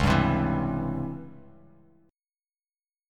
Listen to DbmM7b5 strummed